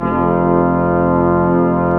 Index of /90_sSampleCDs/Roland LCDP06 Brass Sections/BRS_Quintet/BRS_Quintet long